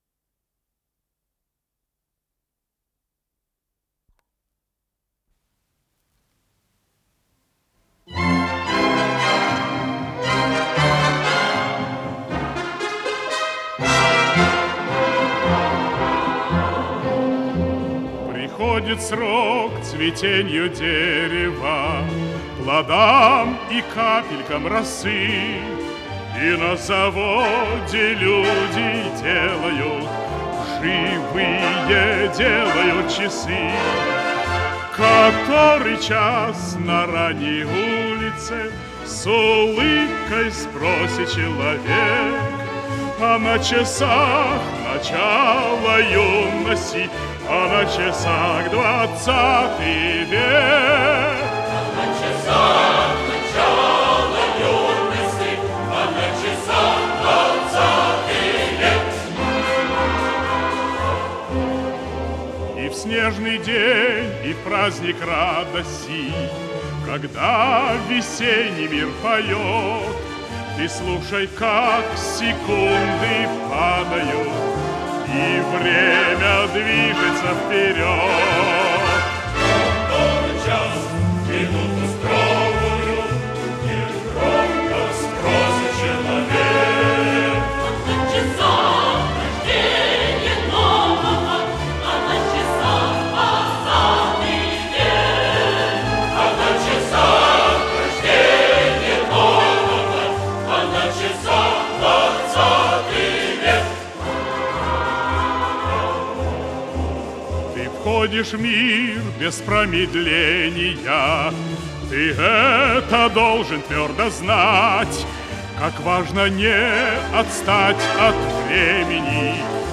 ВариантДубль моно